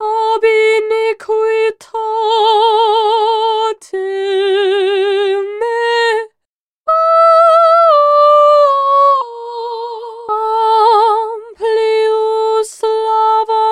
I have two audio tracks, one playing a loop from one of my tunes and the other one a female vocal phrase.